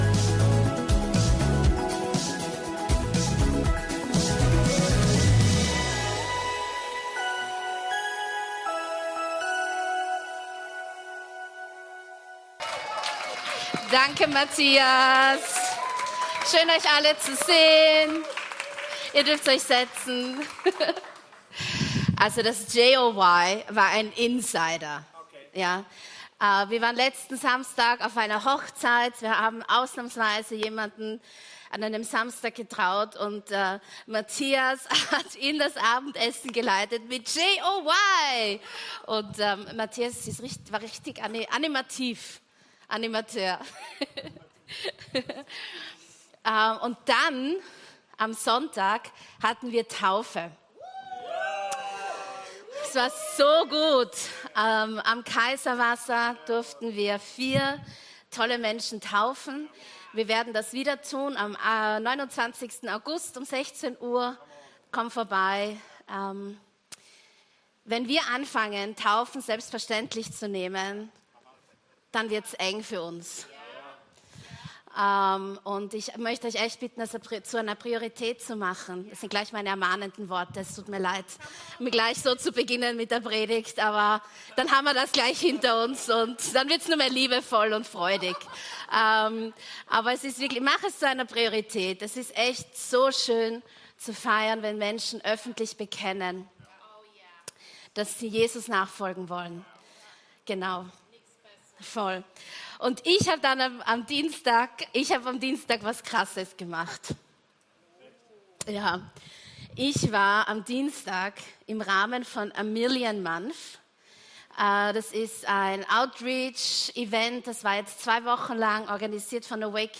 Gottesdienst aus der LIFE Church Wien.